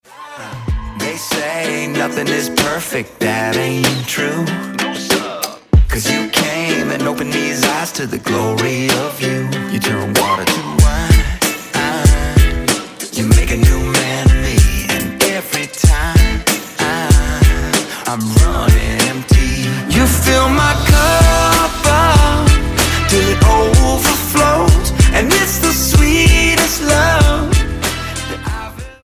STYLE: Pop
something of a reggae groove